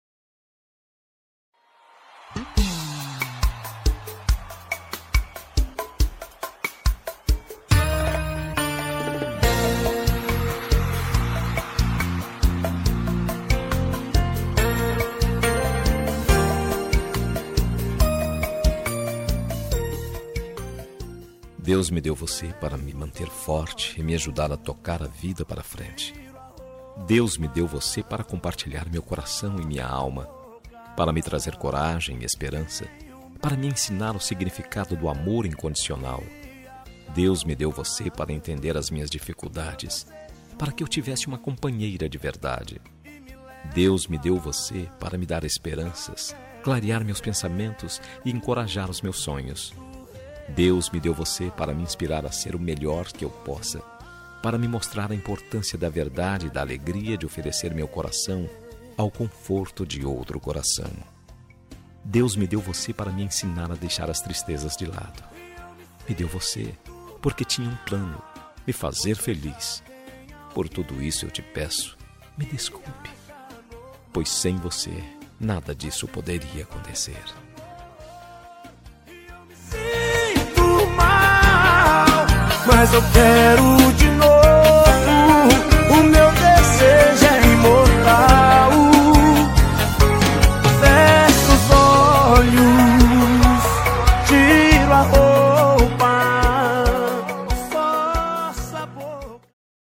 Telemensagem de Reconciliação – Voz Masculina – Cód: 8004 – Linda